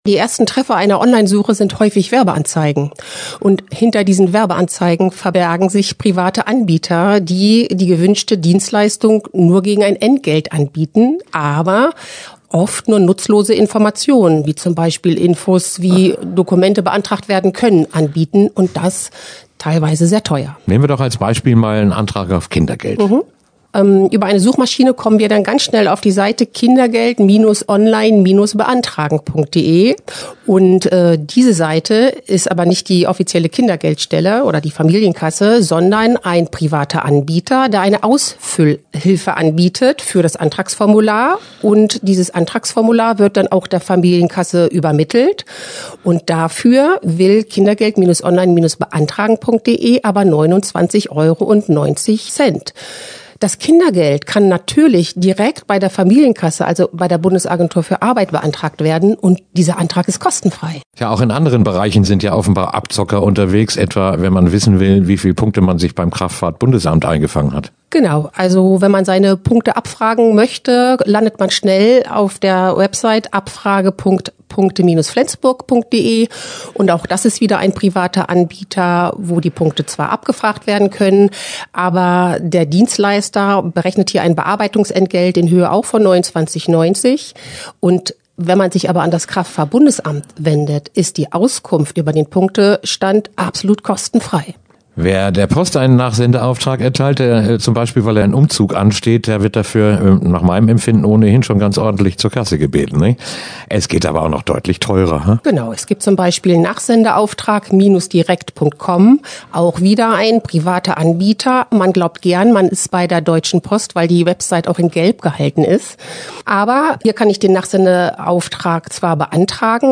Verbraucherschutz im Gespräch: Expertin warnt vor Fake-Angeboten für Behördendokumente im Netz - Okerwelle 104.6
Interview-Verbraucher-24-10-Behoerden-Dokumente.mp3